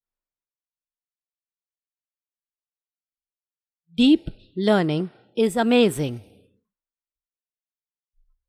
Updated Audio with Clear vocals